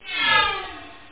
laser.mp3